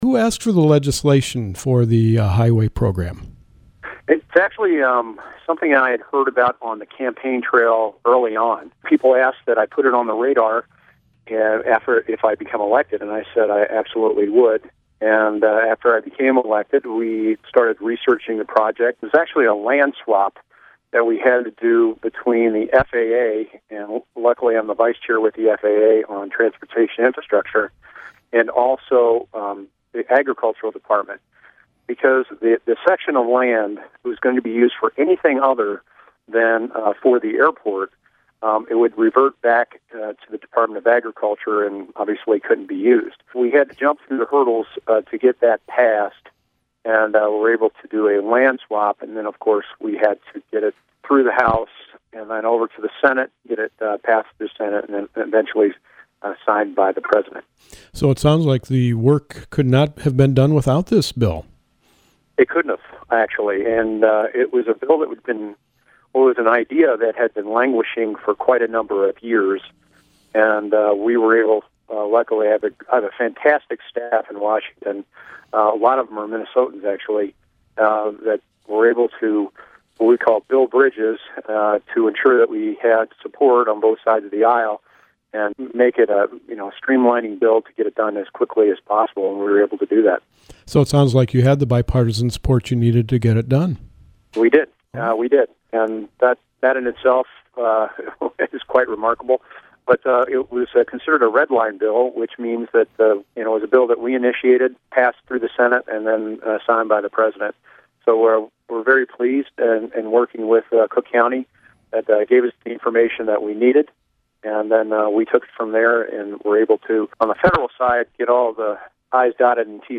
Cravaack Interview.mp3